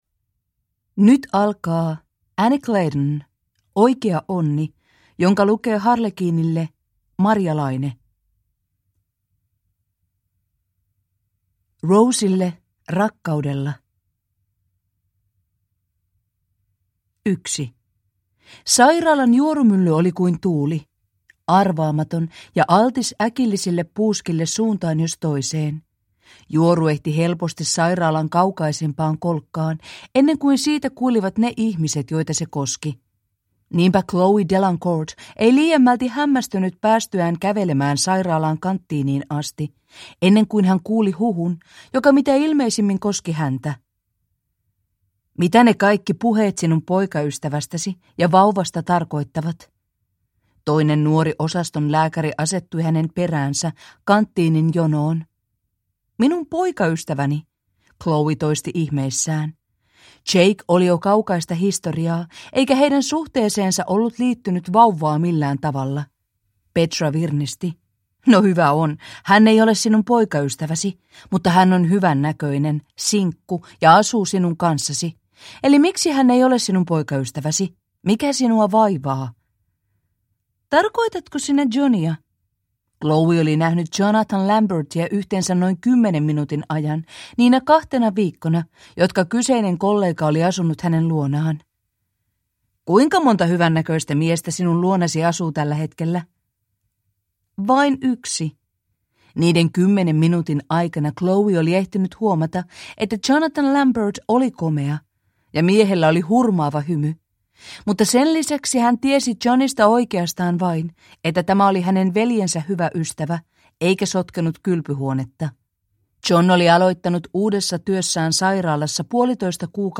Oikea onni (ljudbok) av Claydon Annie